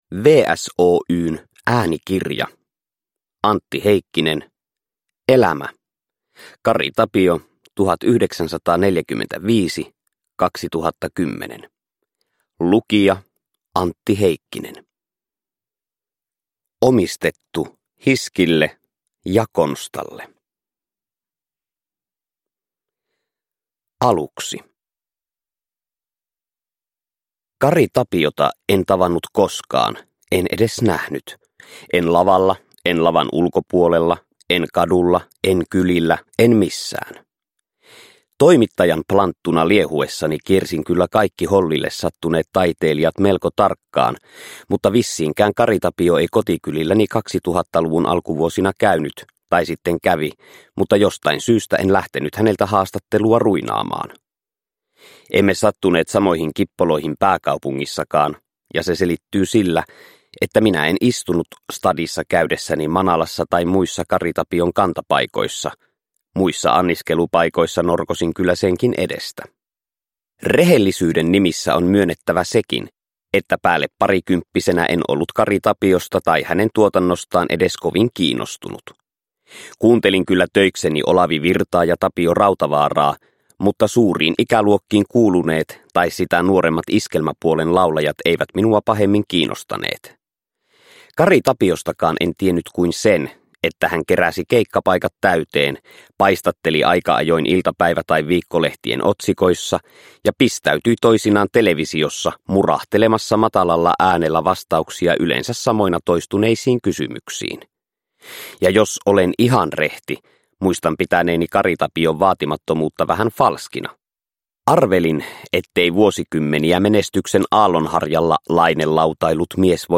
Kari Tapio. Elämä – Ljudbok – Laddas ner